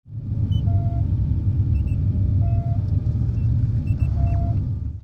vehicleLevel.wav